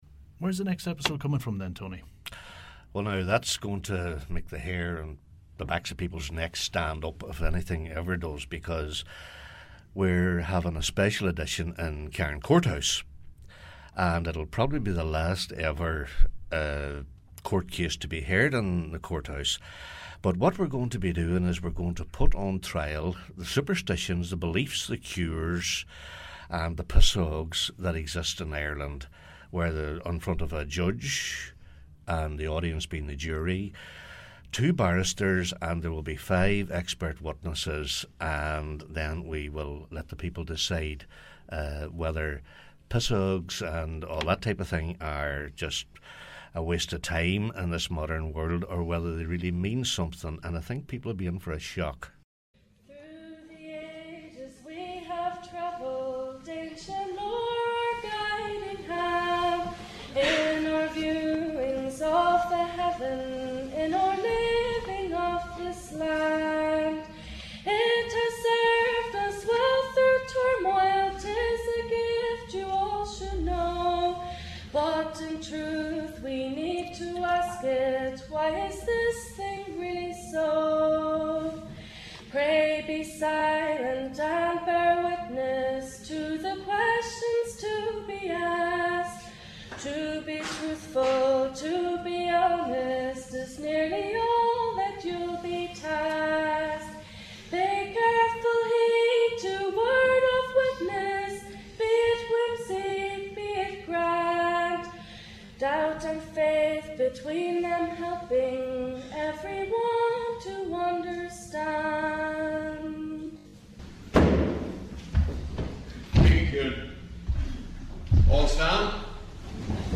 The result was 'The Twilight Court', which is a mock court trial under which superstition itself is on trial to be decided by the audience at the 'court' on any given evening.
A number of common topics associated with superstitions and customs are examined with an “expert witness” such a seventh son of a seventh son being cross-examined by counsel. The topics include good and bad omens, births and deaths, love and marriage, charms and cures as well as fairies and sprites.
Audience participation was encouraged with any tales that they may have heard on the night.